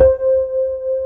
BAS.FRETC5-L.wav